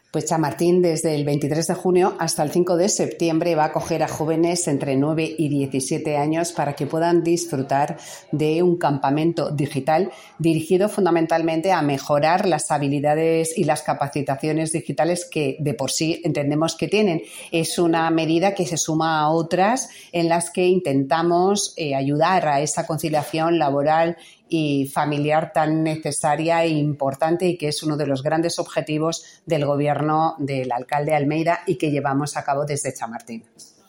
Nueva ventana:Declaración de la concejala de Chamartín, Yolanda Estrada.